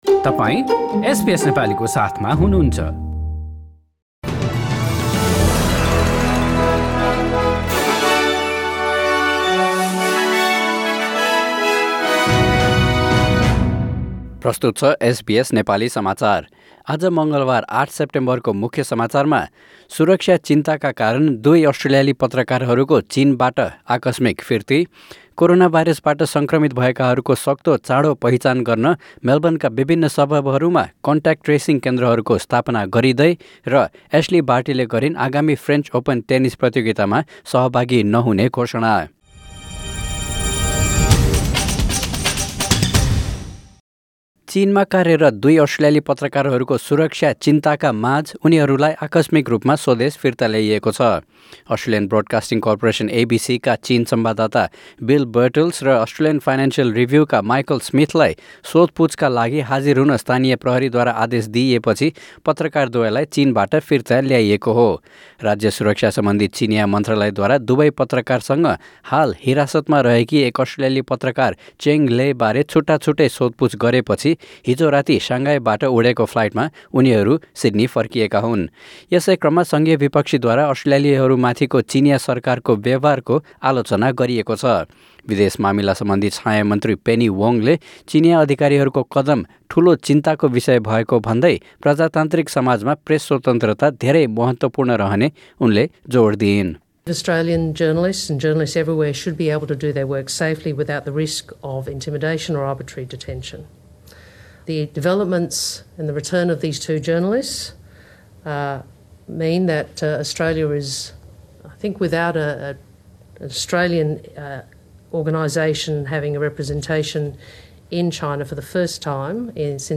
एसबीएस नेपाली अस्ट्रेलिया समाचार: मङ्गलबार ८ सेप्टेम्बर २०२०